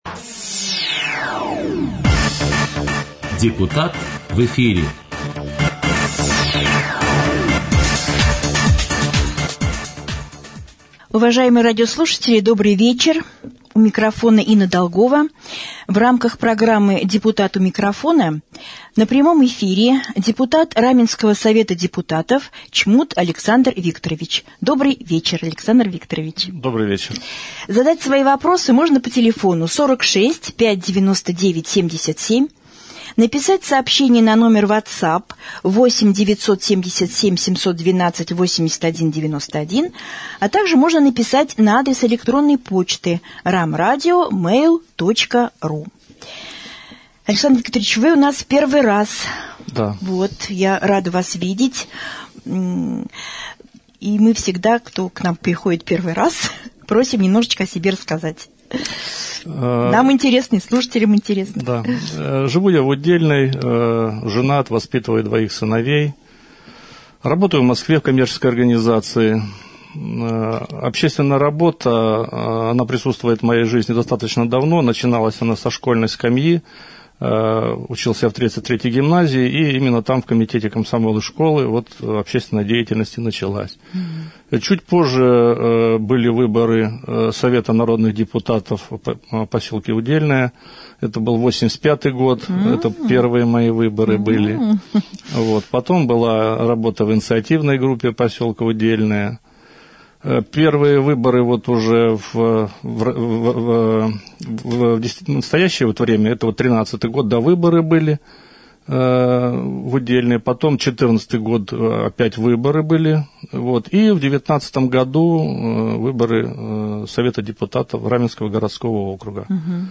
Депутат Совета депутатов Раменского городского округа стал гостем прямого эфира на Раменском радио в четверг, 13 августа.